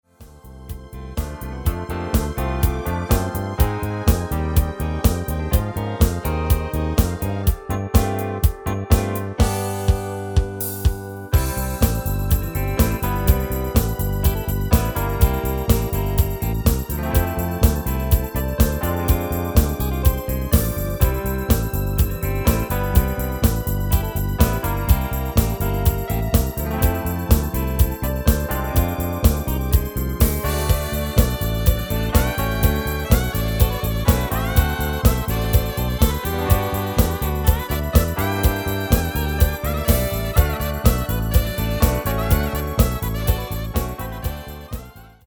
Demo/Koop midifile
Genre: Disco
Toonsoort: C
- Vocal harmony tracks
Demo = Demo midifile